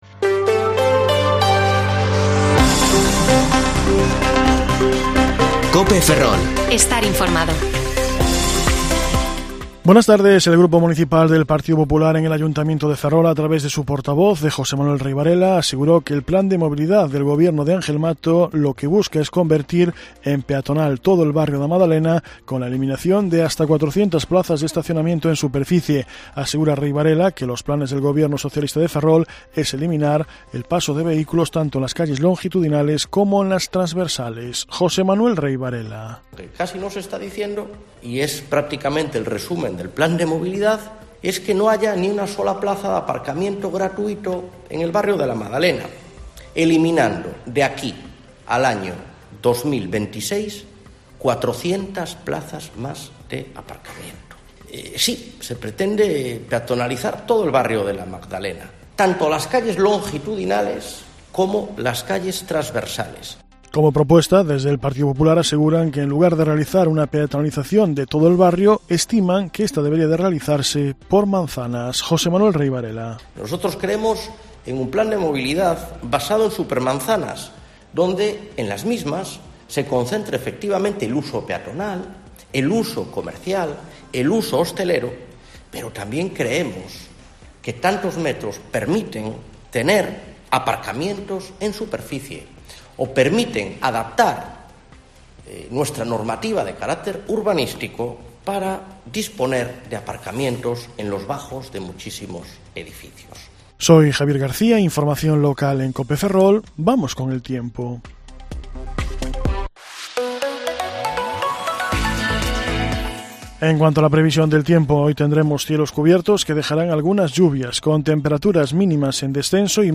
Informativo Mediodía COPE Ferrol 20/6/2022 (De 14,20 a 14,30 horas)